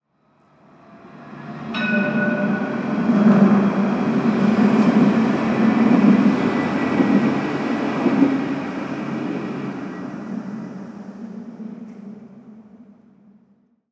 Tram Passing #4
A modern Parisian tram passes by.
UCS Category: Trains / Tram (TRNTram)
Type: Soundscape
Channels: Stereo
Disposition: ORTF
Conditions: Outdoor
Realism: Realistic
Equipment: SoundDevices MixPre-3 + Neumann KM184